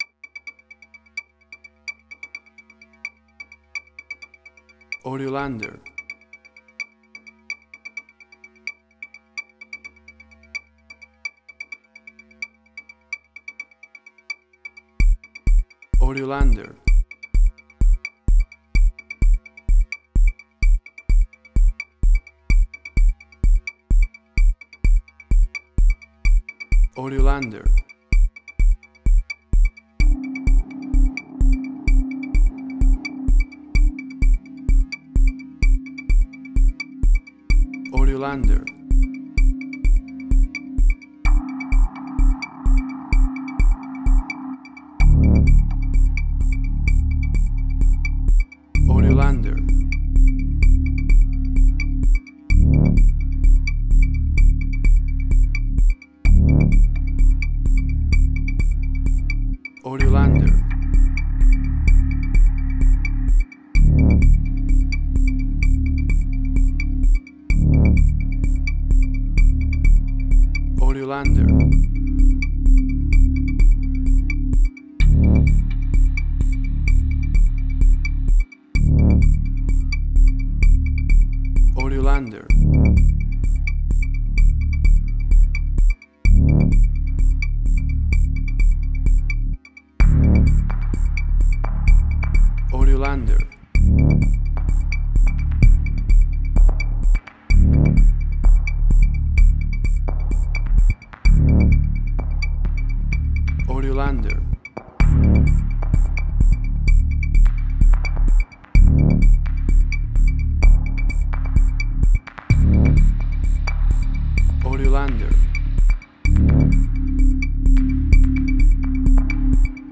Modern Science Fiction Film, Similar Tron, Legacy Oblivion.
WAV Sample Rate: 16-Bit stereo, 44.1 kHz
Tempo (BPM): 128